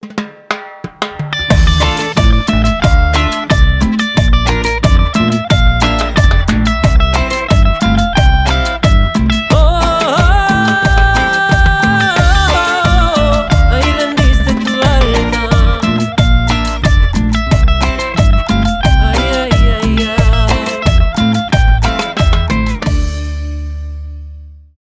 Скачать рингтон
заводные , гитара , инструментальные , танцевальные